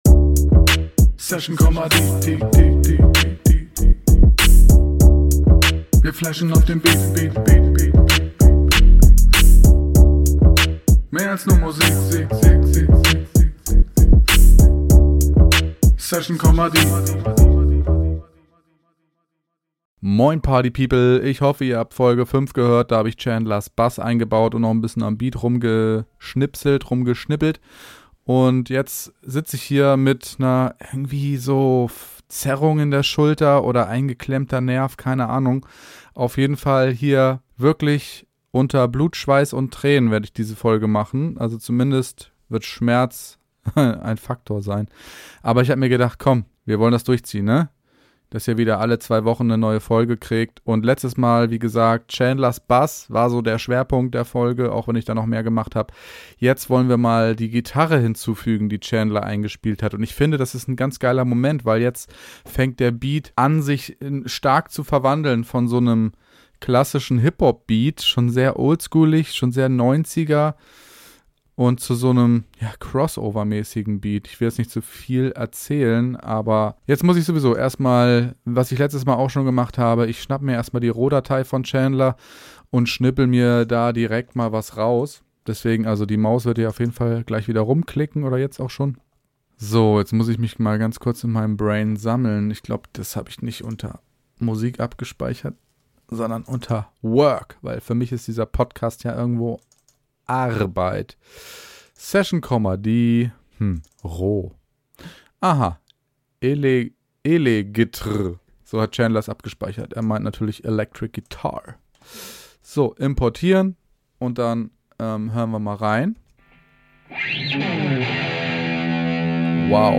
In dieser Folge wird der Rap-Beat zum Rock-Beat.